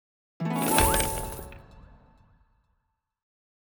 Harp Money 1.wav